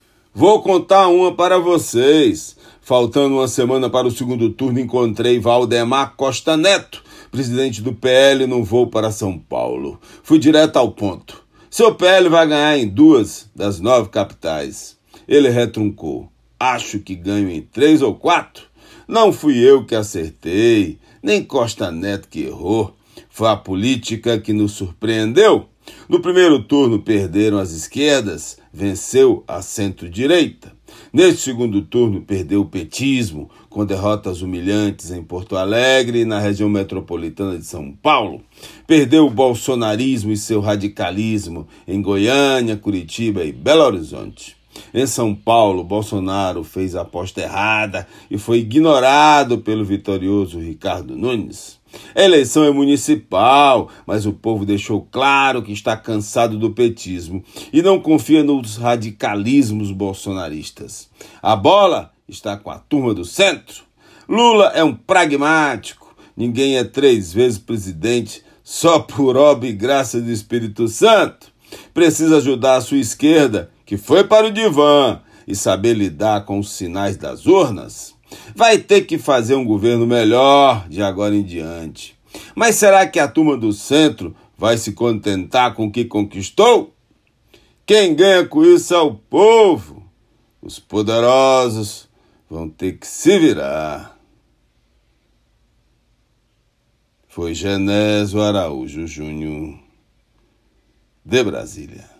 COMENTÁRIO DIRETO DE BRASIL